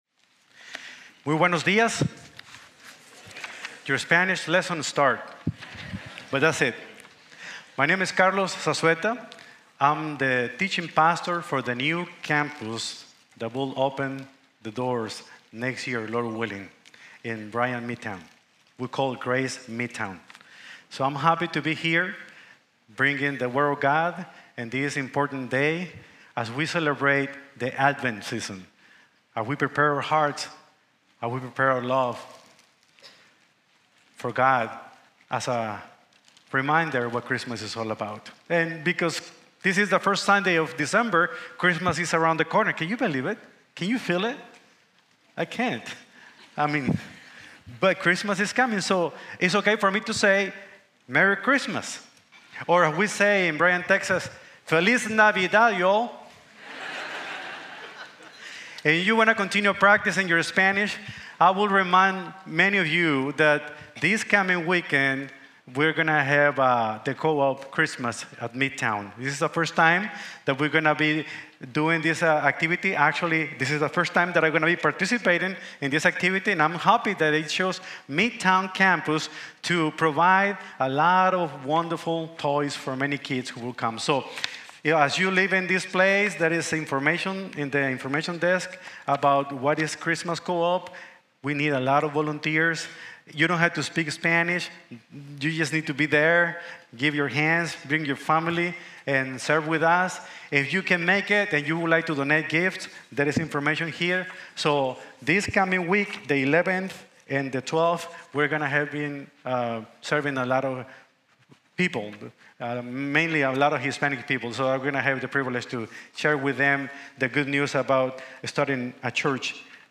Jesus: God’s Gift of Real Peace | Sermon | Grace Bible Church